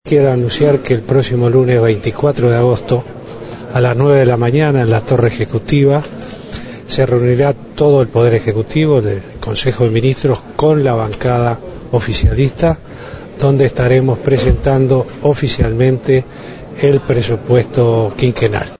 Vázquez sostuvo en diálogo con la prensa en Brasilia, que se barajan dos o tres fórmulas para financiar las obras, que no sobrecarguen las cuentas públicas.